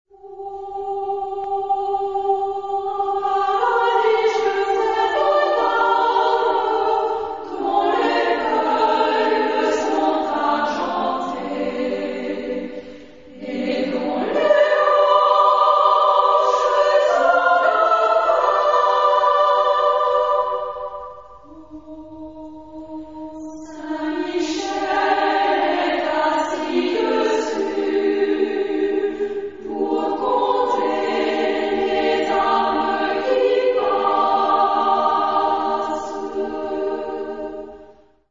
Charakter des Stückes: poetisch
Tonart(en): G-Dur